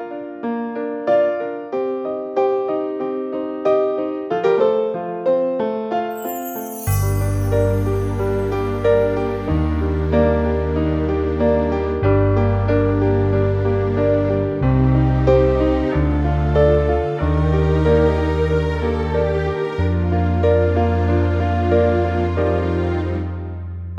Minus Piano Pop (1970s) 4:19 Buy £1.50